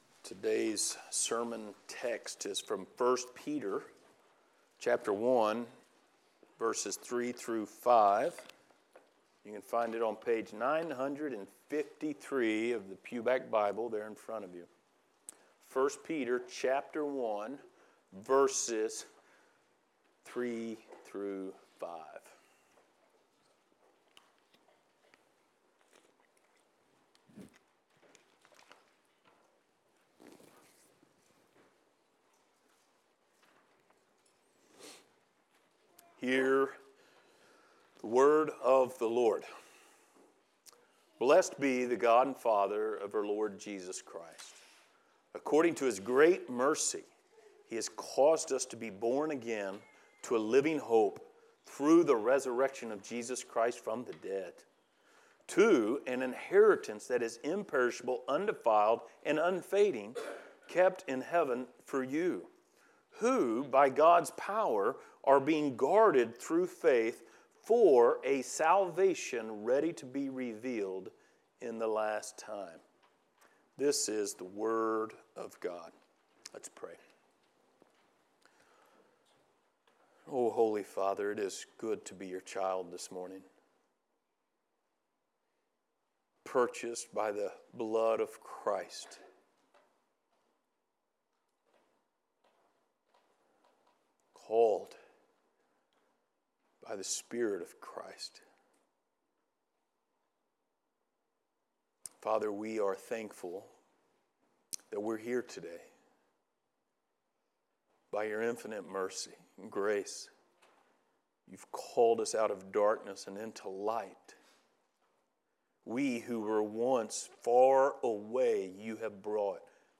Passage: 1 Peter 1:3-5 Service Type: Sunday Morning